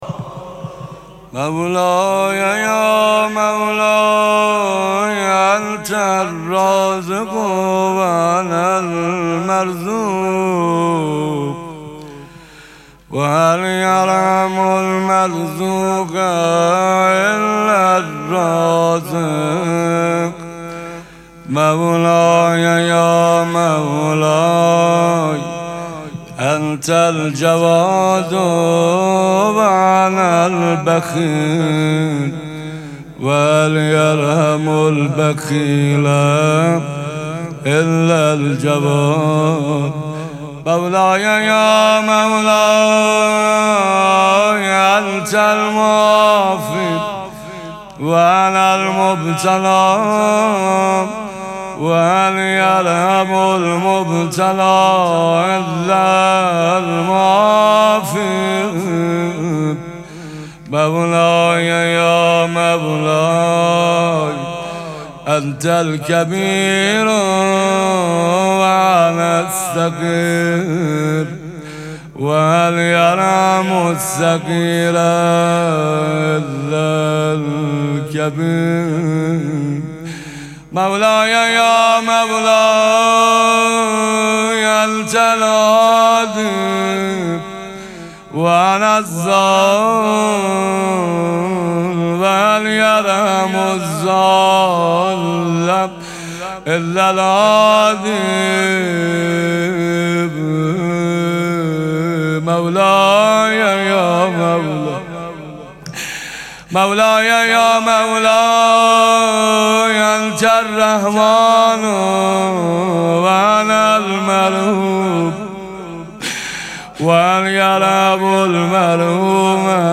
مراسم مناجات شب چهاردهم ماه مبارک رمضان
مداح